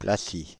Placy (French pronunciation: [plasi]
Fr-Paris--Placy.ogg.mp3